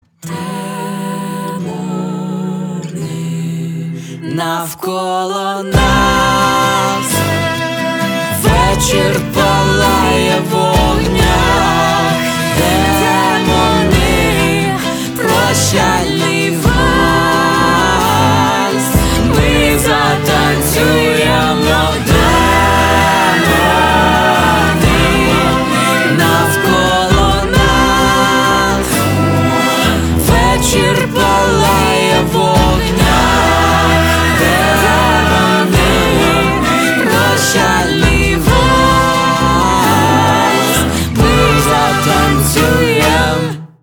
• Качество: 320, Stereo
дуэт
лирические
красивый женский вокал
украинский рок